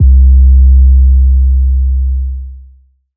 REDD 808 (7).wav